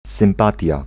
Ääntäminen
US : IPA : [ˈɡɝl.ˌfɹɛnd]